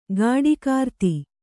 ♪ gāḍikārti